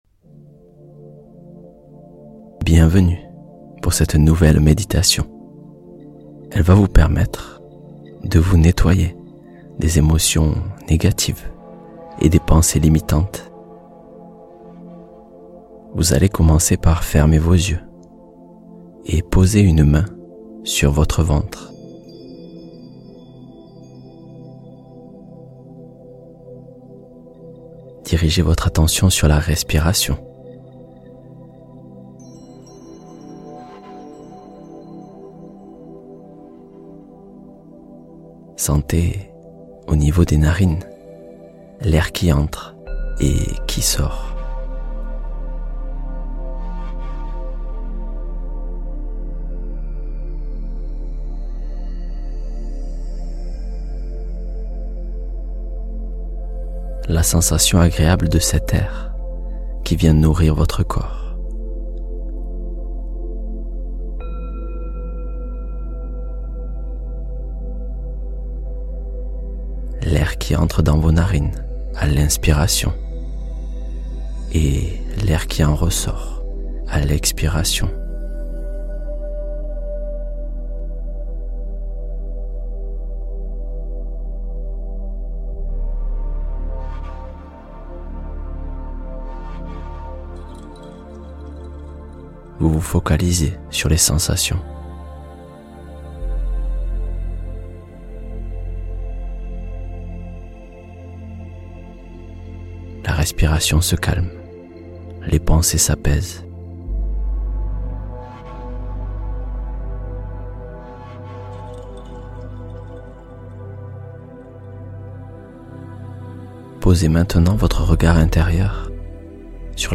Méditation Guidée: Le podcast